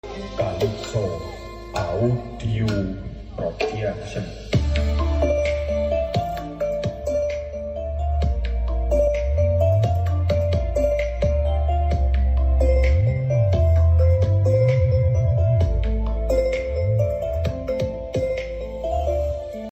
Cek Sound 16 Subwoofer.